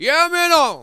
VOX SHORTS-1 0023.wav